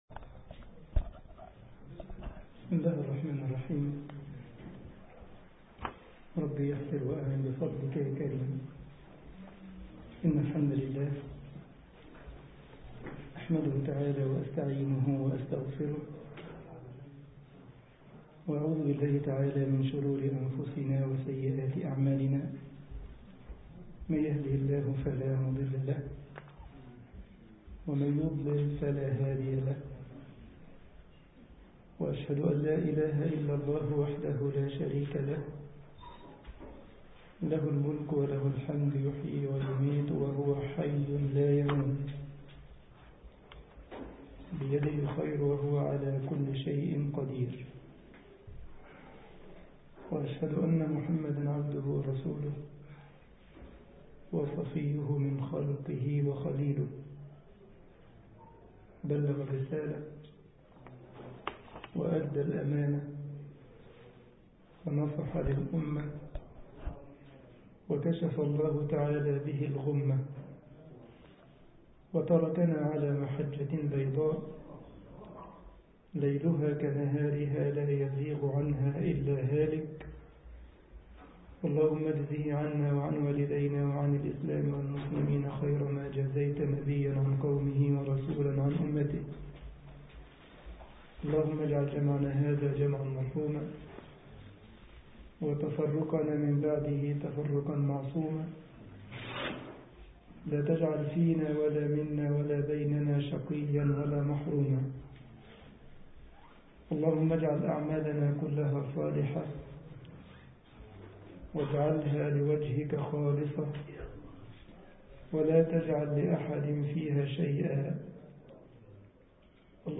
مسجد الجمعية الإسلامية بكايزرسلاوترن ـ ألمانيا